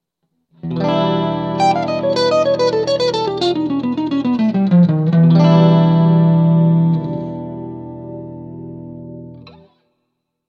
Govan Minor Blues Jam!